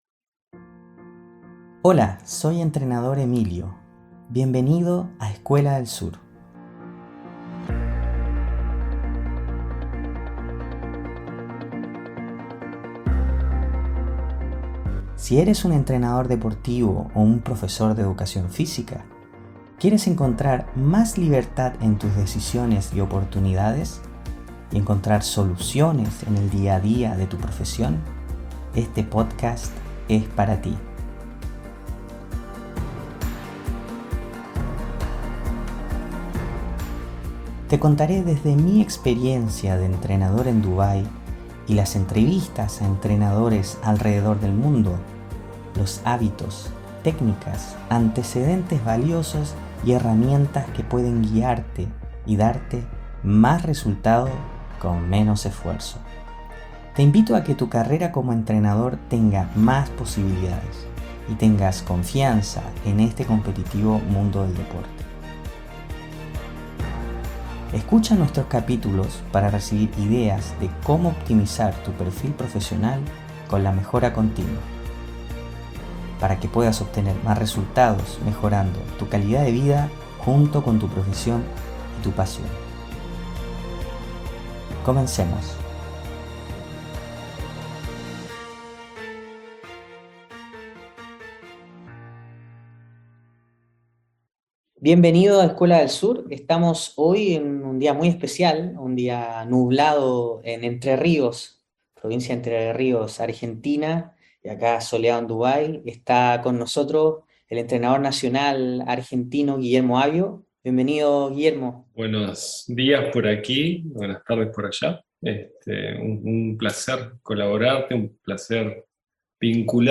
Escucha la entrevista también en Spotify, Apple Podcast, Youtube y LinkedIn Síguenos en nuestras redes soci